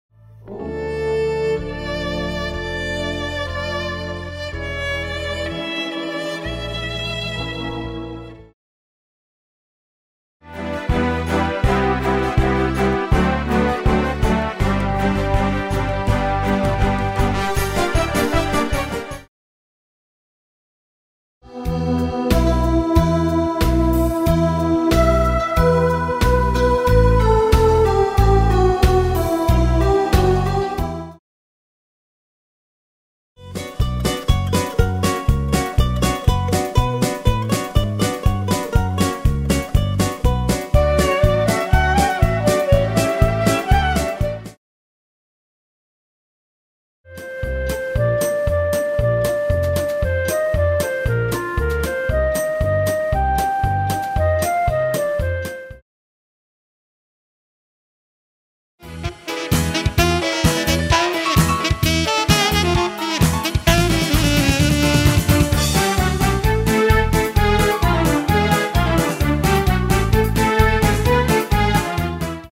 חלקי ניגונים לתחנה 2
חלקי-ניגונים-תחנה-2.mp3